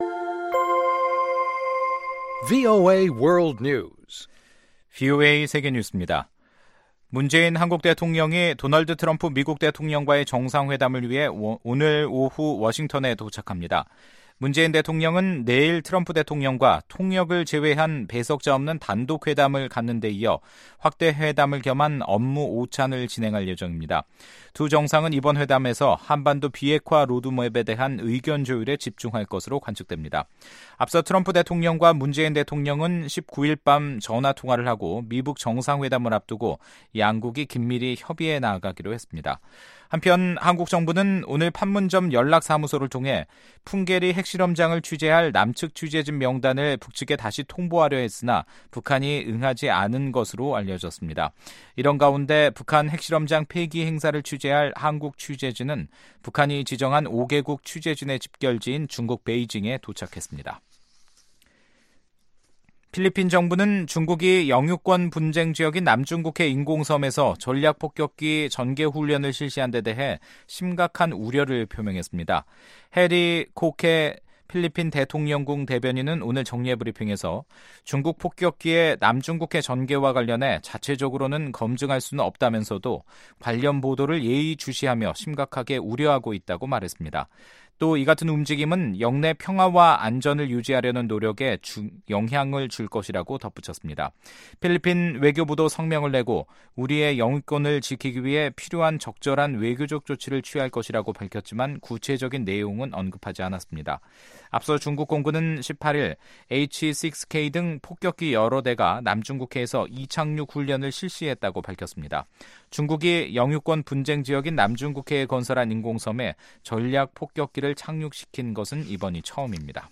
세계 뉴스와 함께 미국의 모든 것을 소개하는 '생방송 여기는 워싱턴입니다', 2018년 5월 21일 저녁 방송입니다. 미국이 중국산 수입품에 신규 관세 시행을 보류합니다. 지난 2016년 미국 대선 기간에 FBI가 트럼프 캠프에 정보원을 심었다는 의혹과 관련해 트럼프 대통령이 법무부에 조사를 요구했습니다. '구석구석 미국 이야기'에서는 커피 주문부터 제조까지 척척 해내는 로봇 바리스타를 소개합니다.